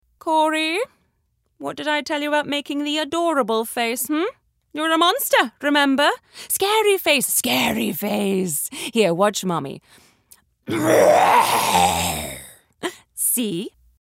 Animation V/O - Heightened British Accent
Overachieving 'A Type' Mother